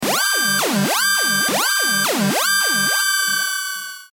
• インパクト予告（牙狼斬馬剣）